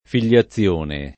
figliazione [ fil’l’a ZZL1 ne ]